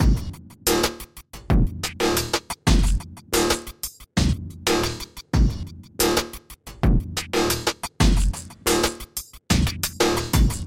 描述：砰砰砰
Tag: 90 bpm Electronic Loops Drum Loops 1.95 MB wav Key : Unknown